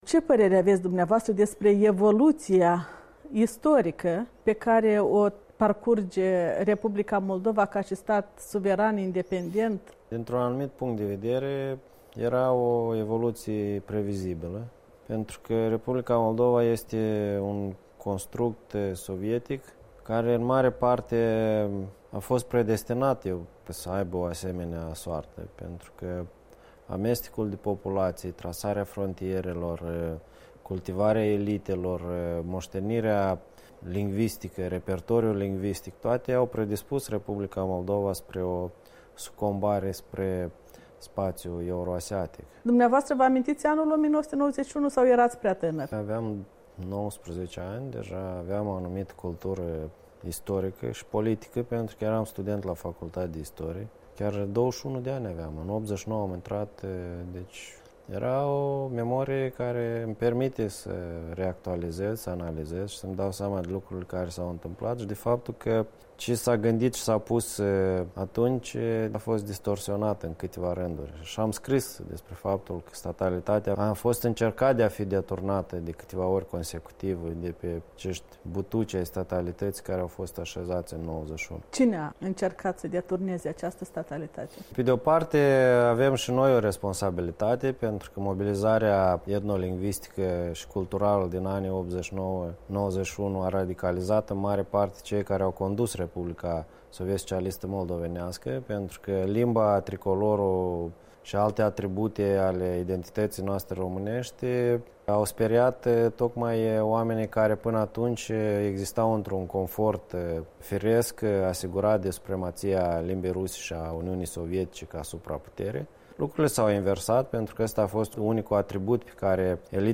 De vorbă cu istoricul Octavian Țîcu despre independența R.Moldova